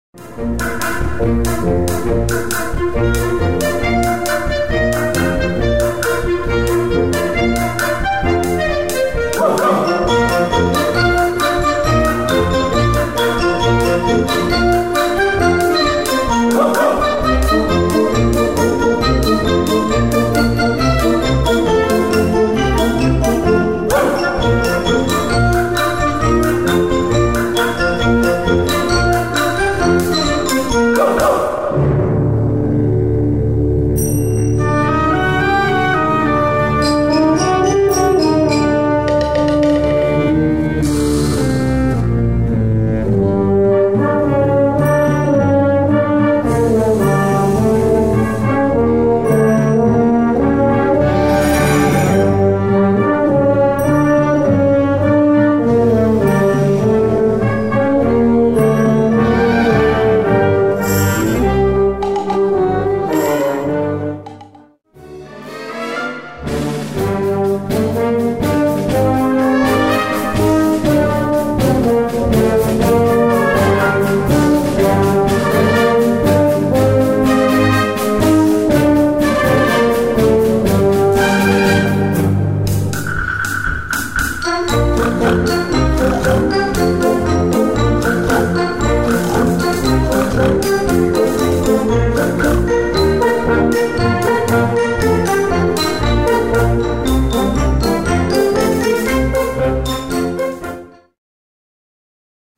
Gattung: Jugendwerk
A4 Besetzung: Blasorchester Zu hören auf